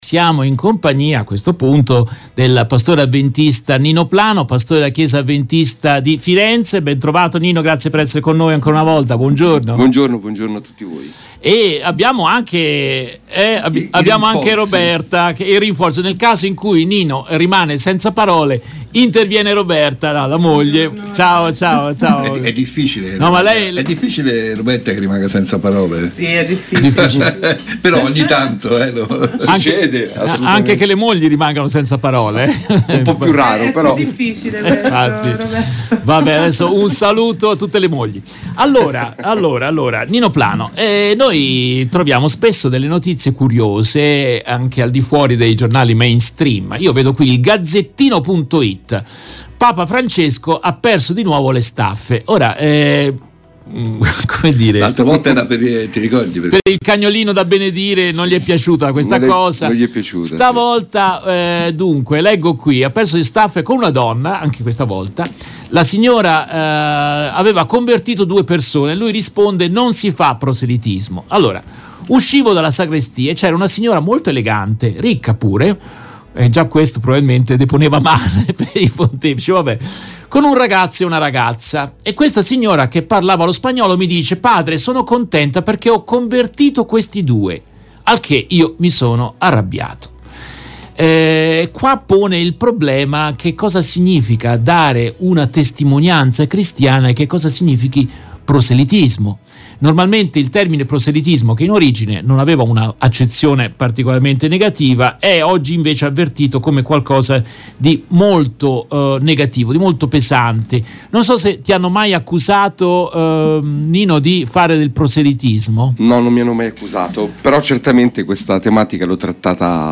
Nel corso della diretta RVS del 31 maggio 2023